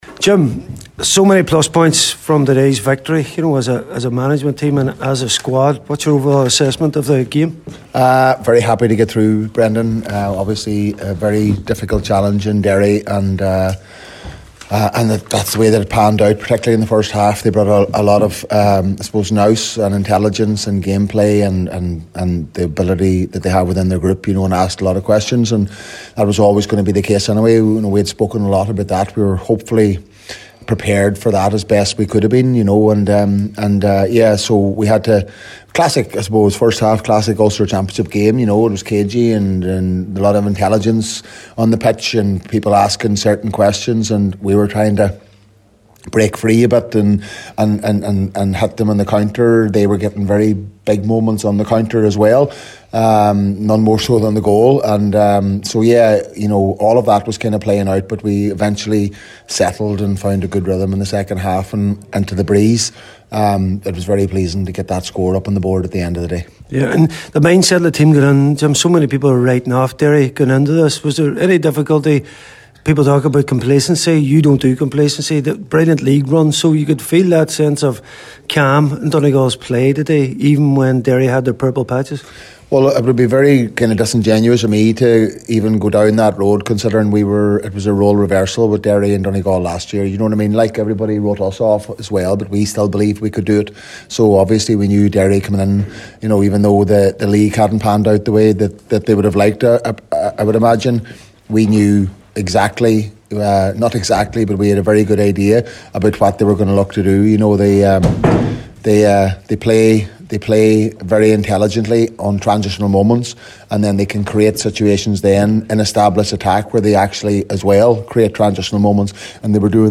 Jim McGuinness in conversation
After today’s game